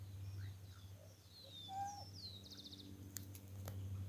Mergulhão-caçador (Podilymbus podiceps)
Nome em Inglês: Pied-billed Grebe
Detalhada localização: Villa Paranacito, Area Protegida Arroyo Las Palmas
Condição: Selvagem
Certeza: Gravado Vocal